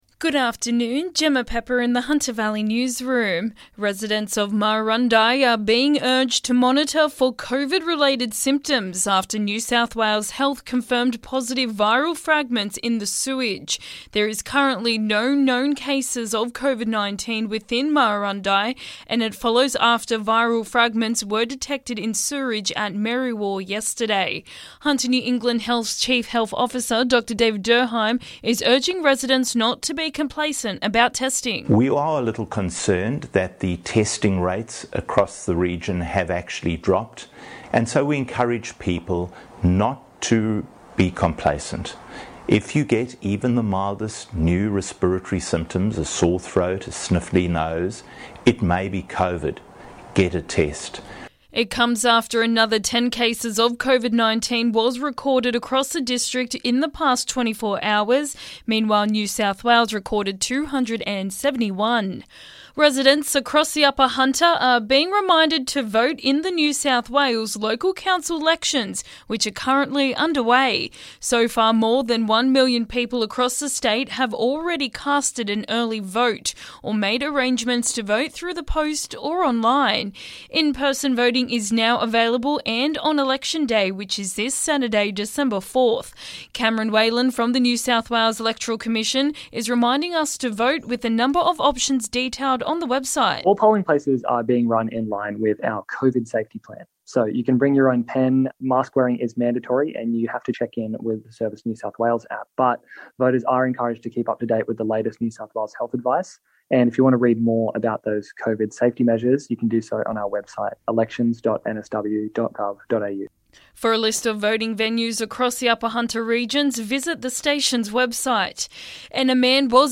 LISTEN: Local Hunter Valley News Headlines